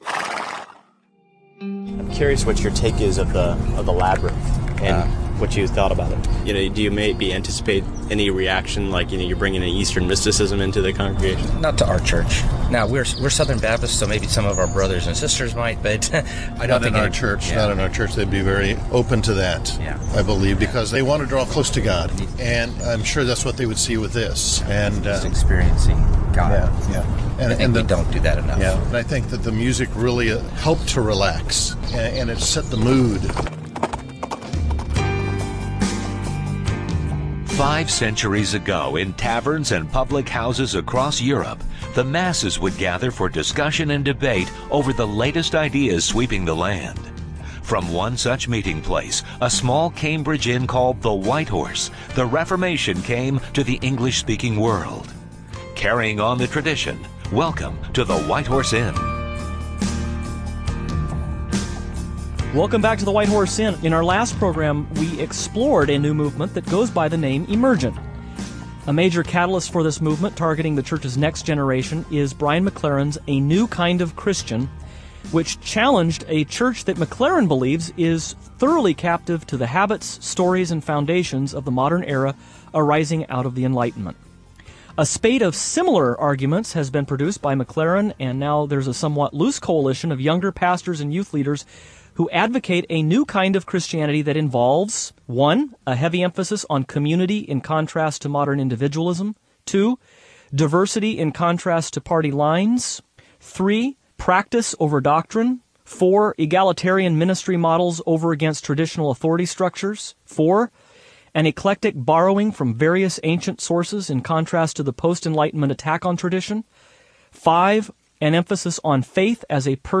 interview
as well as more of our interviews and sound bites from a recent Emergent Church Convention.